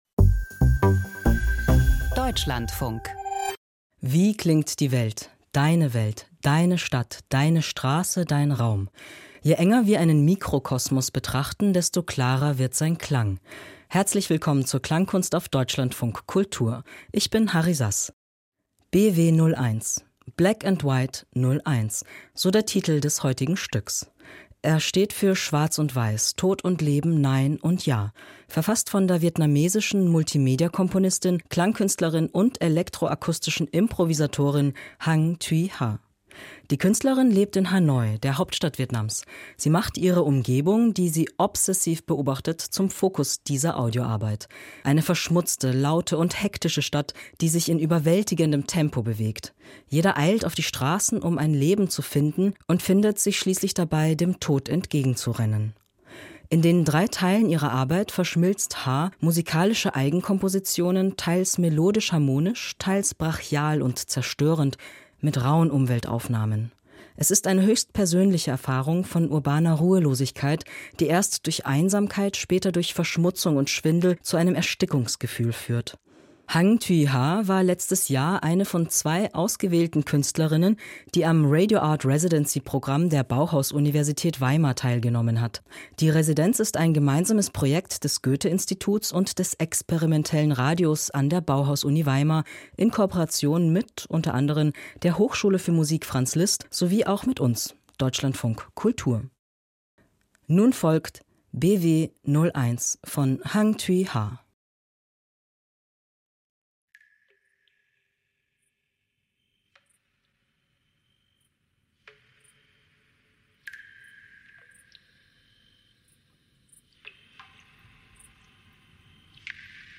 Hörspiel über Kinder, Tod und Trauer - Tod – was soll das?